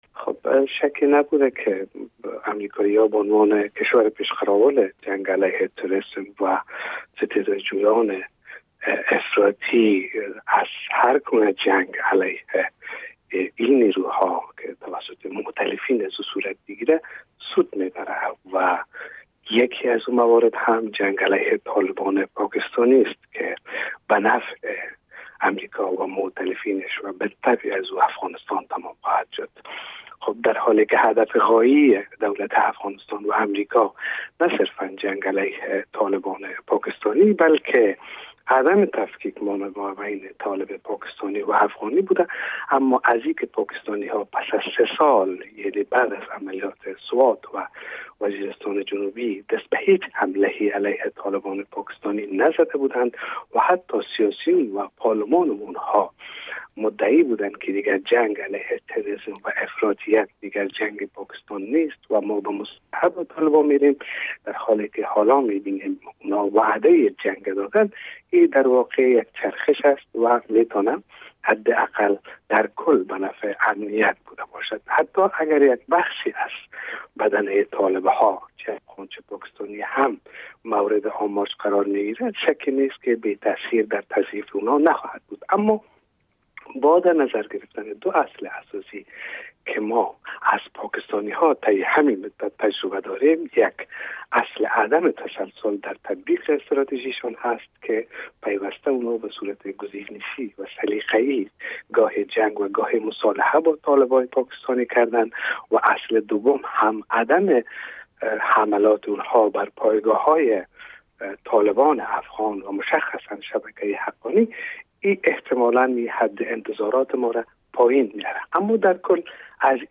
مصاحبه باعتیق الله بریالی در مورد اظهارات اخیرلیون پانیتا د رمورد پاکستان